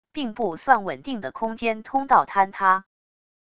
并不算稳定的空间通道坍塌wav音频